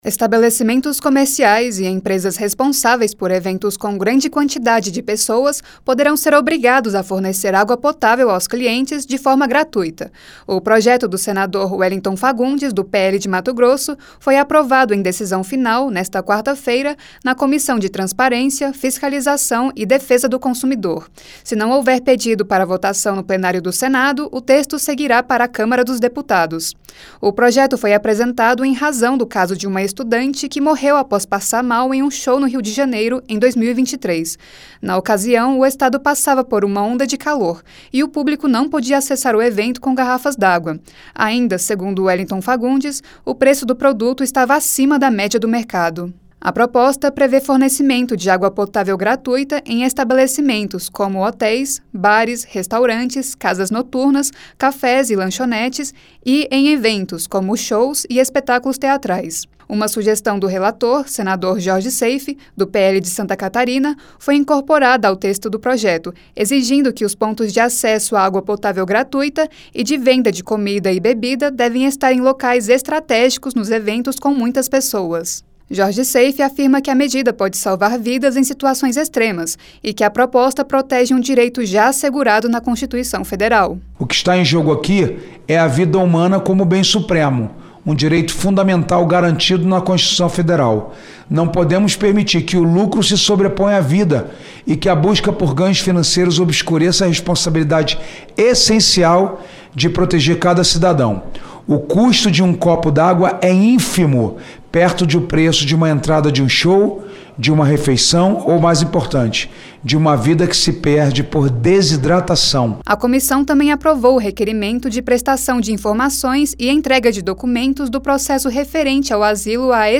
Direito do Consumidor